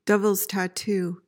PRONUNCIATION:
(dev-uhlz ta-TOO)